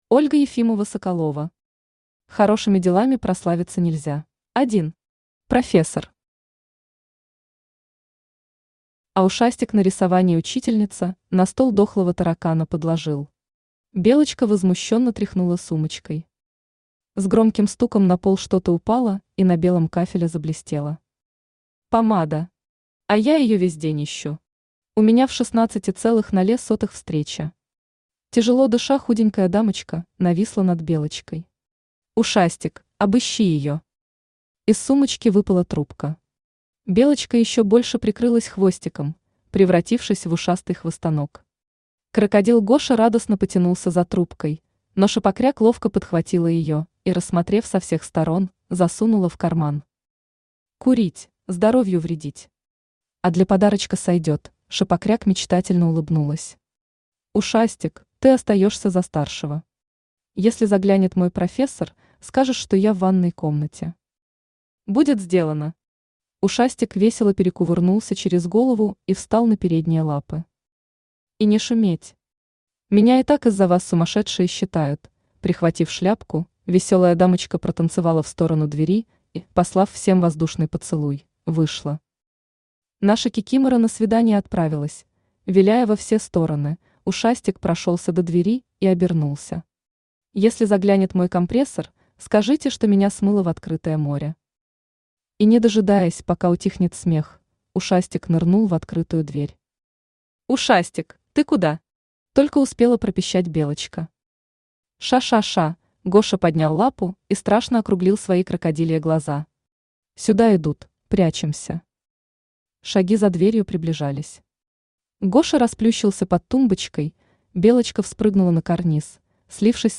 Аудиокнига Хорошими делами прославиться нельзя | Библиотека аудиокниг
Aудиокнига Хорошими делами прославиться нельзя Автор Ольга Ефимова-Соколова Читает аудиокнигу Авточтец ЛитРес.